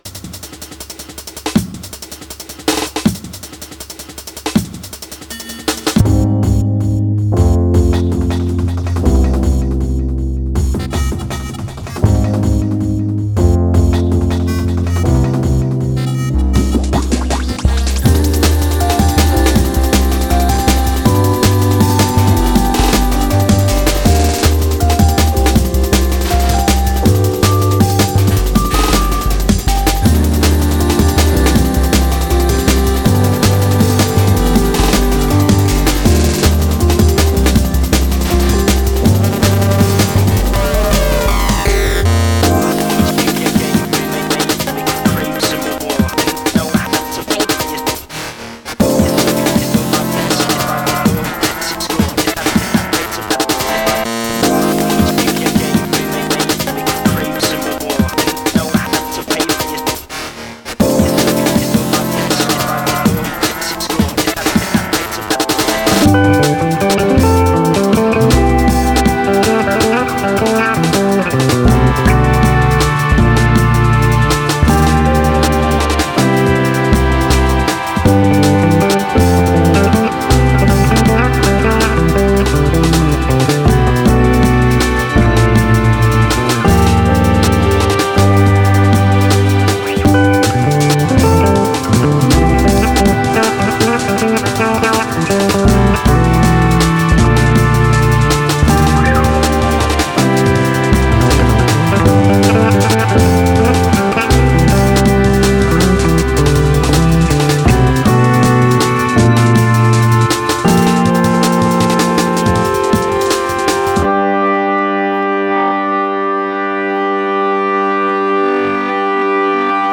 nous plonge dans un univers électro bien agréable